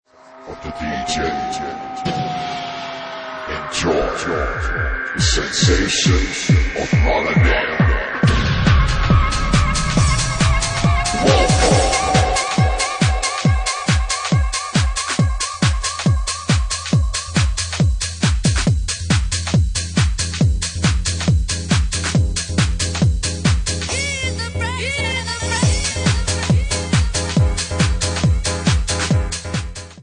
Bassline House at 139 bpm